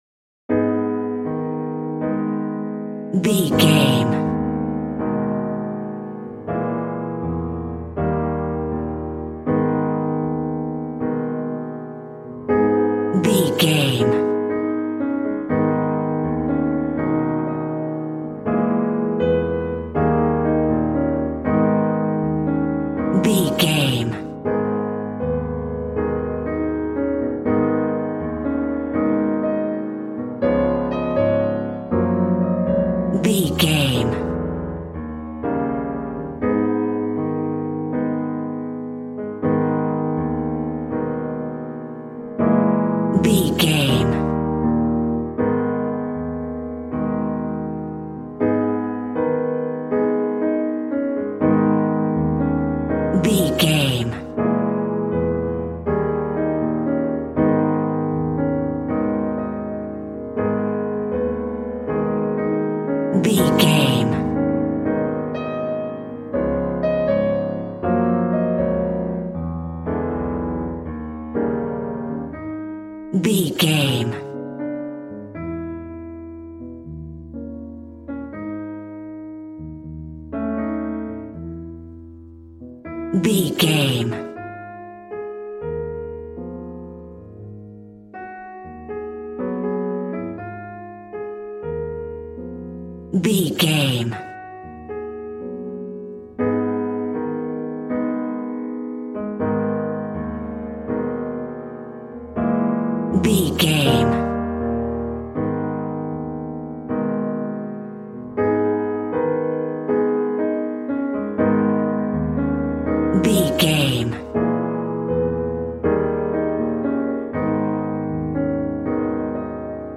Aeolian/Minor
A♭
sexy
smooth
piano
drums